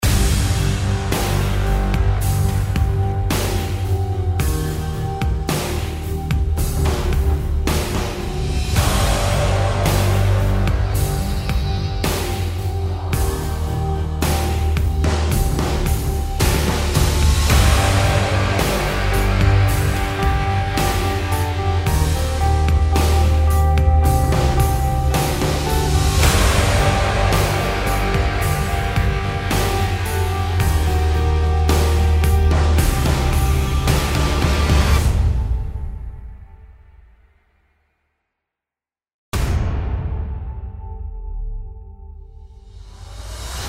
Just say it, this sounds like a trailer cue really man....